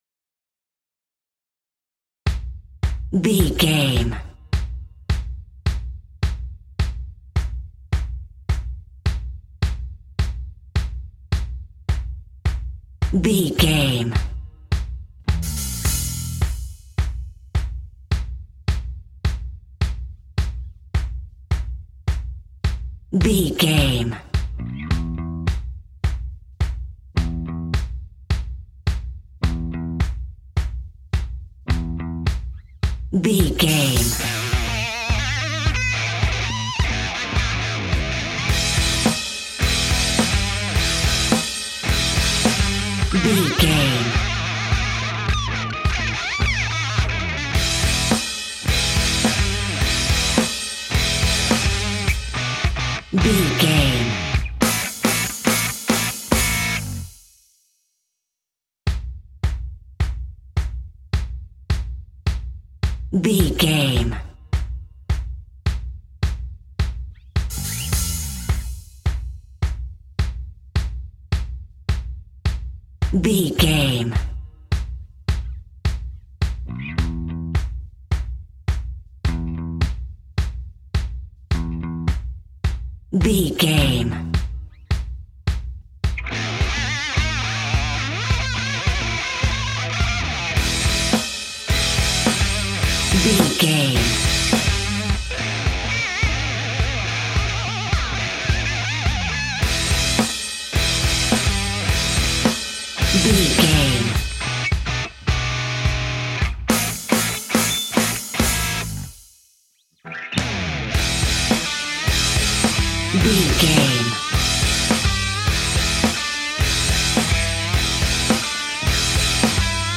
Aeolian/Minor
hard rock
blues rock
distortion
instrumentals
Rock Bass
heavy drums
distorted guitars
hammond organ